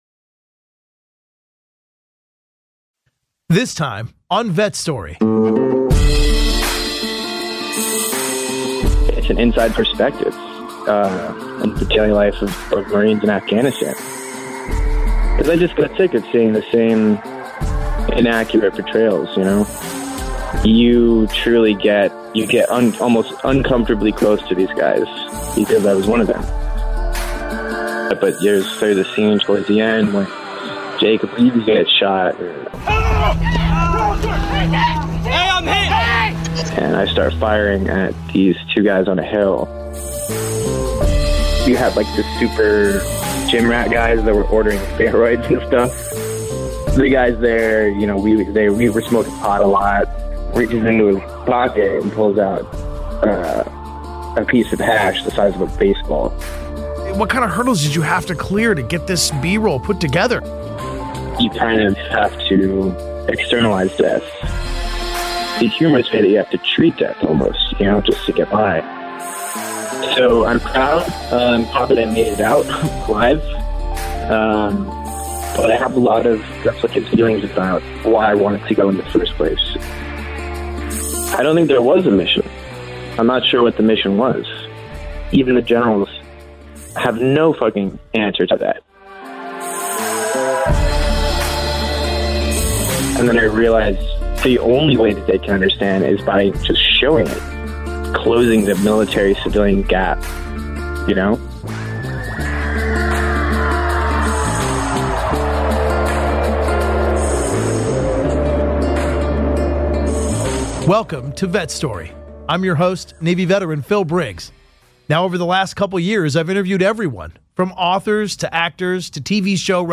The 'Combat Obscura' interview: Insane stories from the front lines